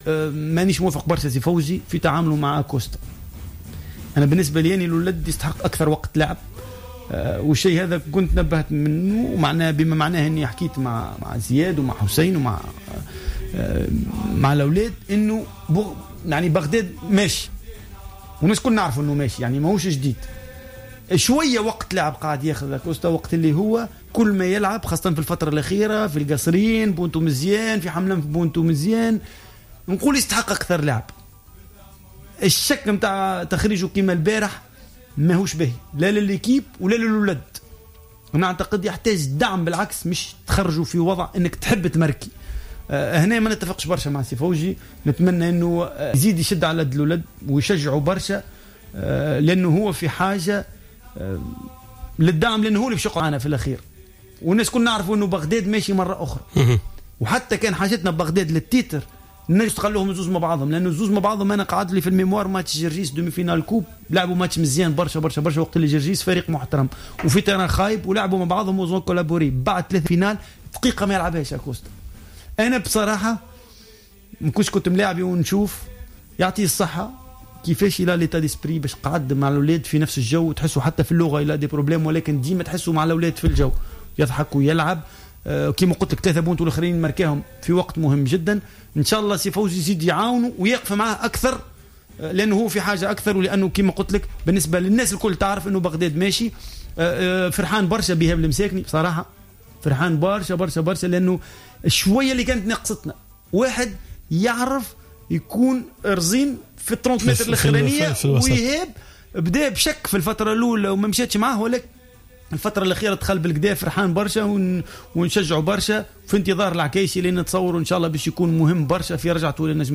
أشار زبير بية ضيف برنامج راديو سبور إلى أنه معجب كثيرا بالمستوى الذي وصل إليه فريق النجم الساحلي خلال الفترة الأخيرة حيث تطور مردود لاعبيه خاصة العناصر الشابة التي كونت نواة جيدة للفريق.